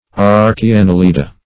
Archiannelida \Ar`chi*an*nel"i*da\, n. pl. [NL.; pref. archi- +